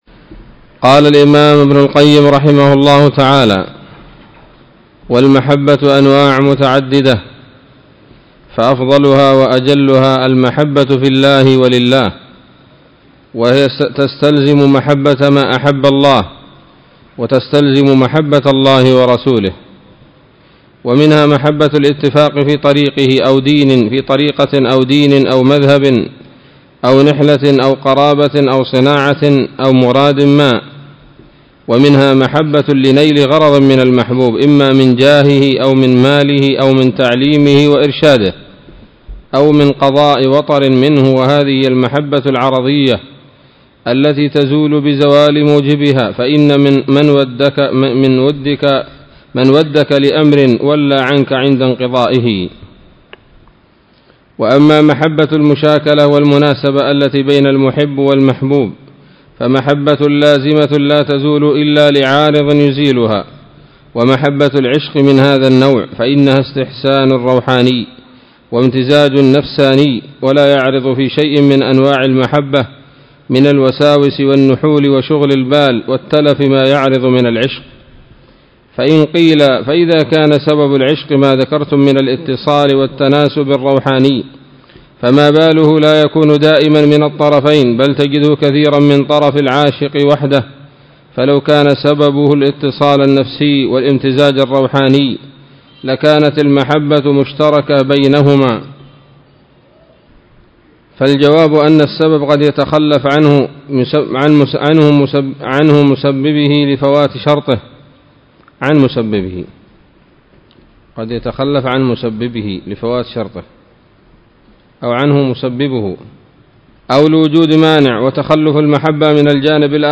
الدرس الثاني والسبعون من كتاب الطب النبوي لابن القيم